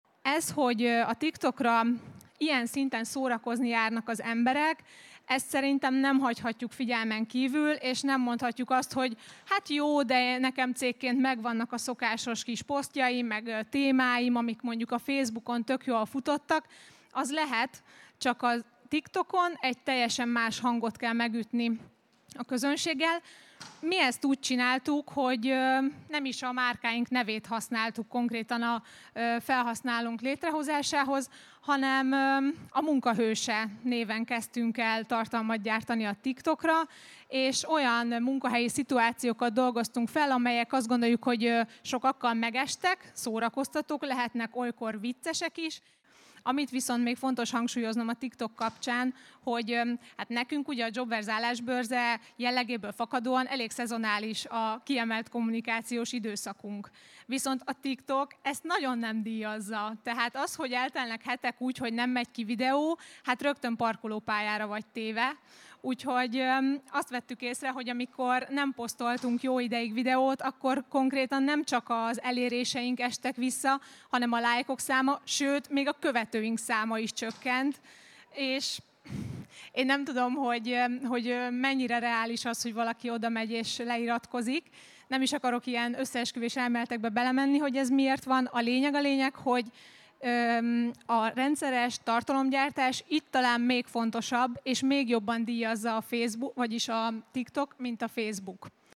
A JOBVERSE-en a Facebook, az Instagram, a LinkedIn és a TikTok kapcsán osztottuk meg azokat a gondolatokat, amelyek HR szakemberként (szerintünk) nem hagyhatók figyelmen kívül. Ezek közül a legújabb – és emiatt talán a legtöbb bizonytalanságot okozó – platform a TikTok.